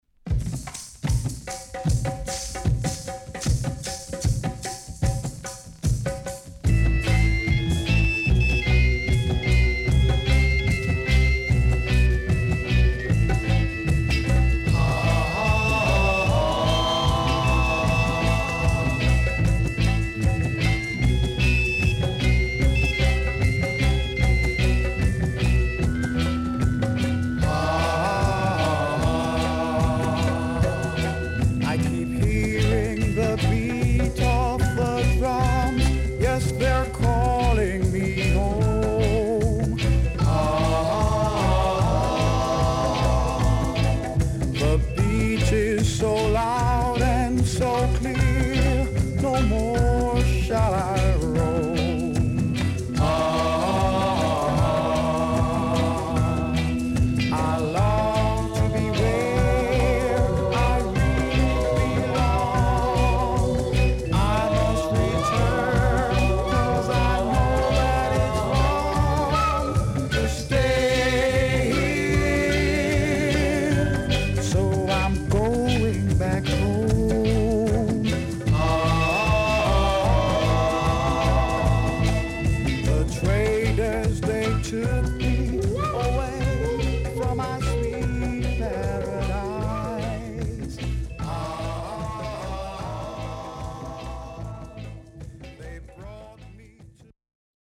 HOME > REISSUE [SKA / ROCKSTEADY]